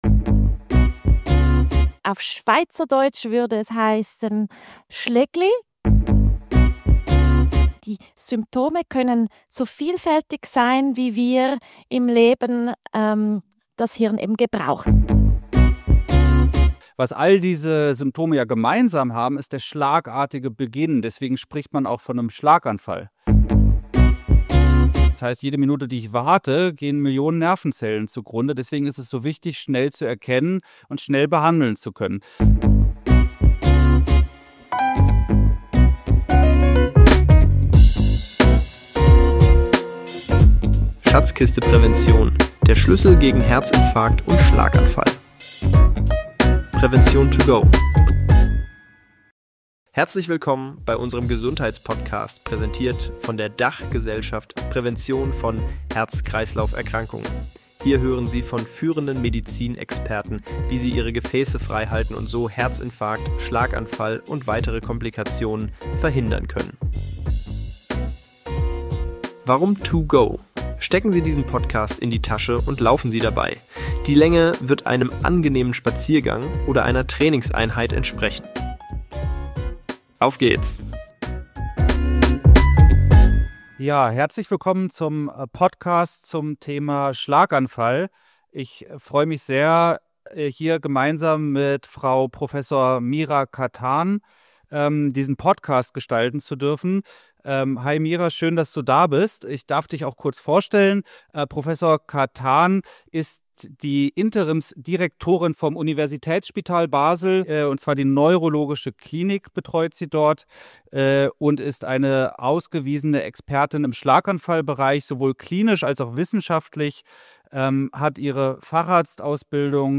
Im Gespräch: